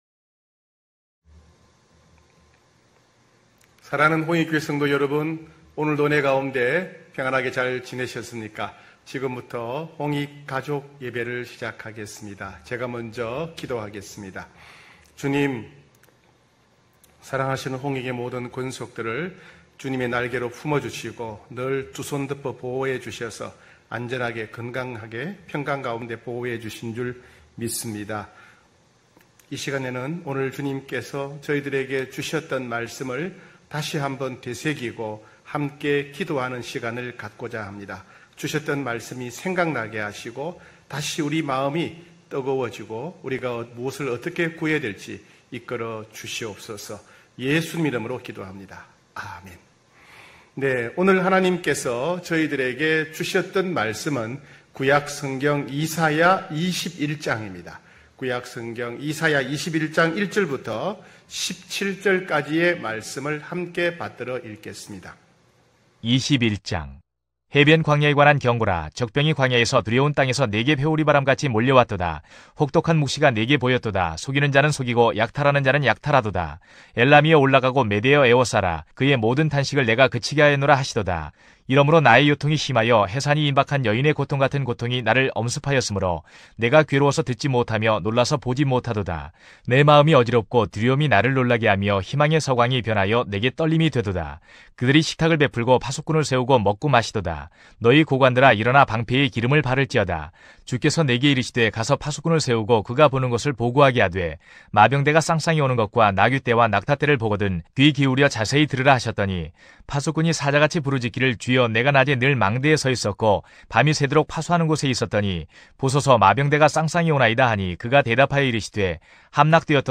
9시홍익가족예배(8월7일).mp3